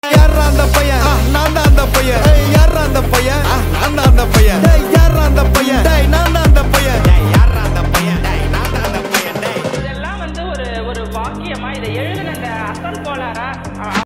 Tamil Songs
Slow Reverb Version
• Simple and Lofi sound
• Crisp and clear sound